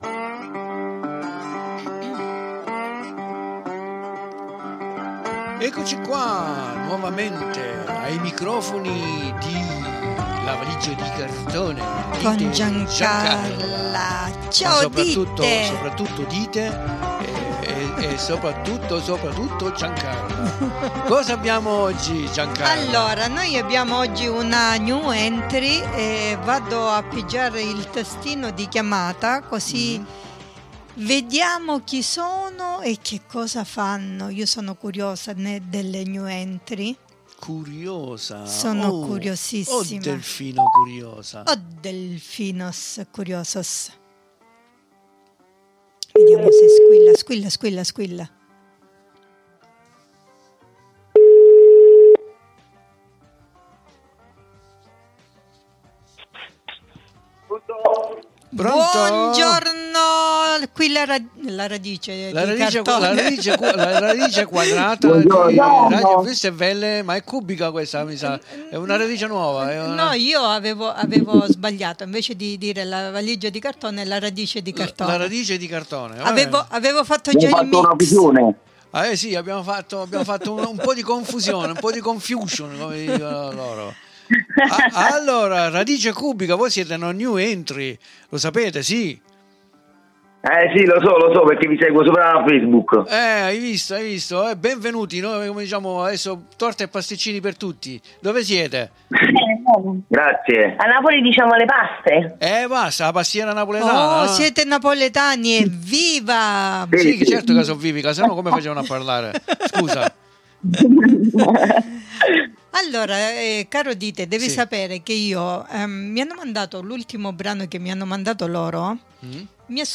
IL RESTO LO ASCOLTERETE DALL'INTERVISTA CONDIVISA QUI IN DESCRIZIONE!
95626_INTERVISTA_Radice_Cubica.mp3